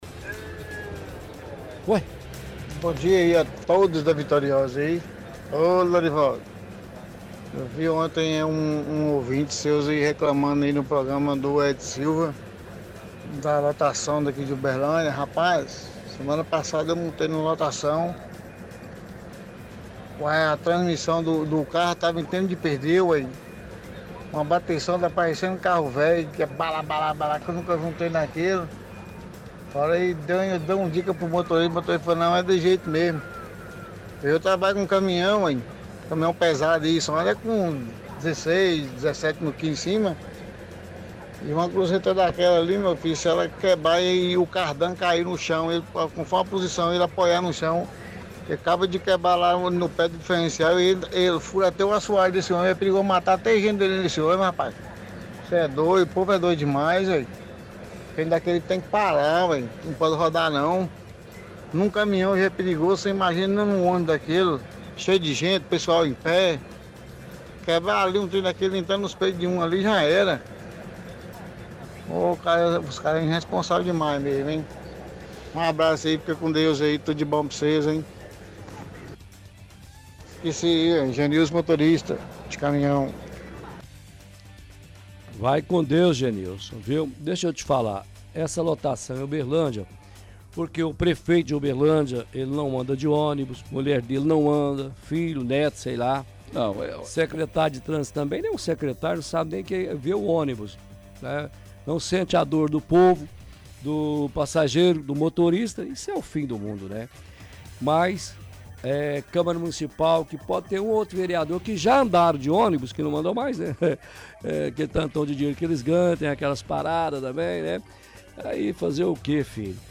– Ouvinte reclama da qualidade do transporte público.